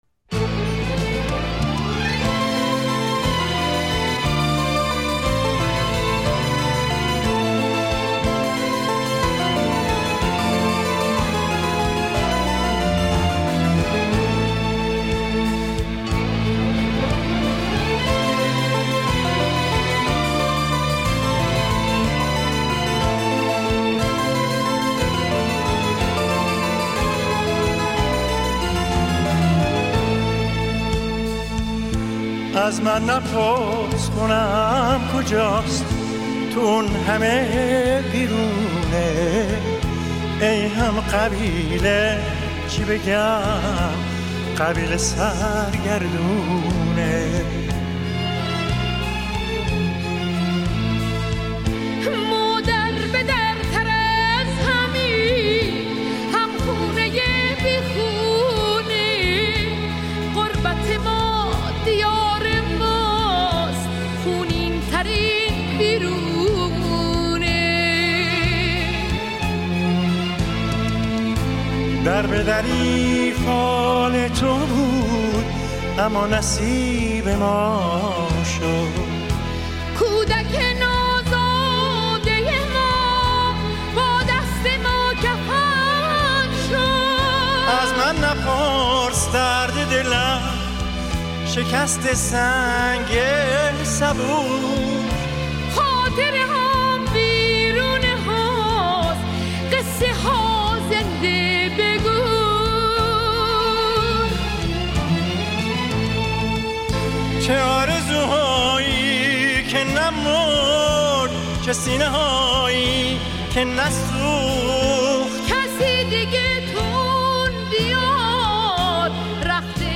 اهنگ نوستالوژی
اهنگ غمگین